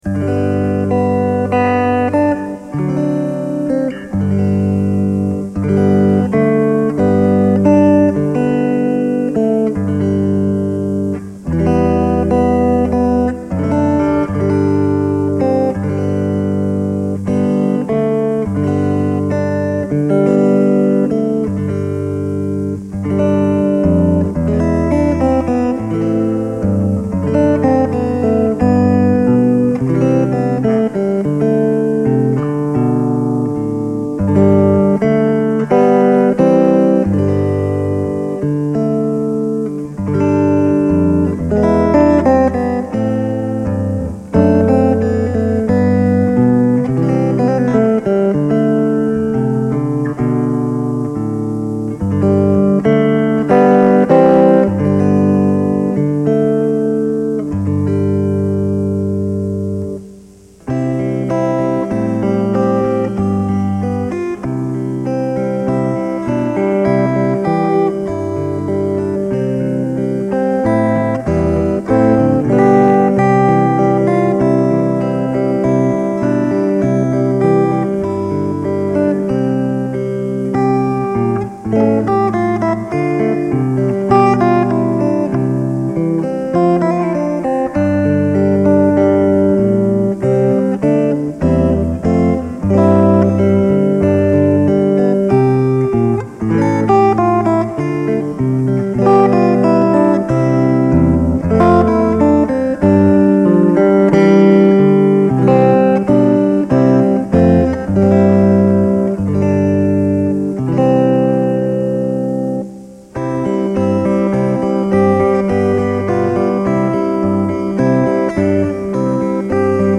Fingerstyle